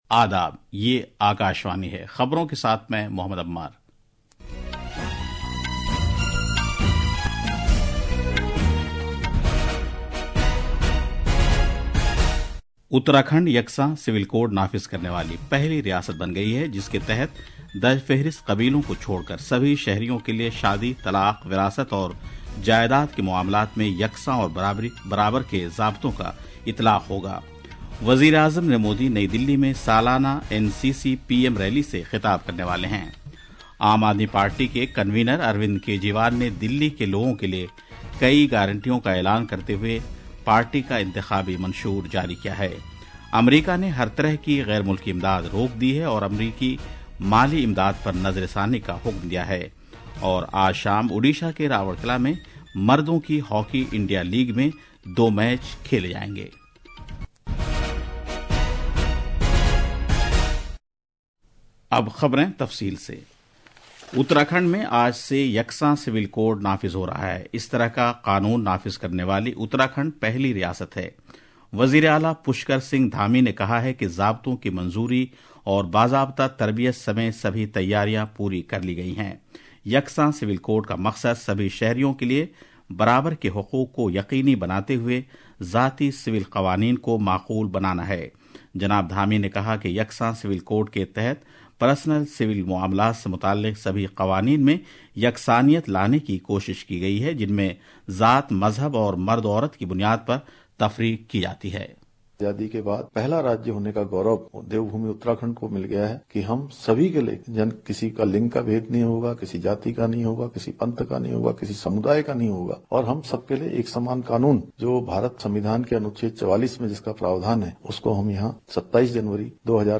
জাতীয় বুলেটিন